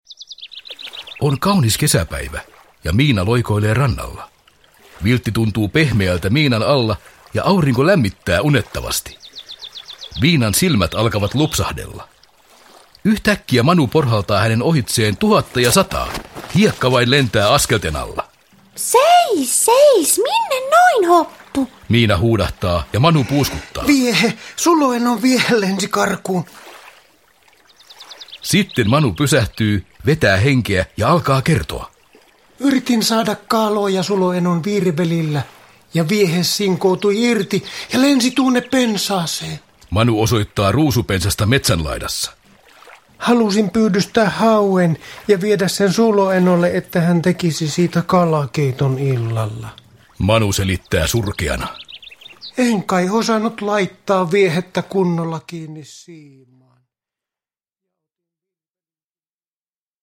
Miina ja Manu ensiavussa – Ljudbok – Laddas ner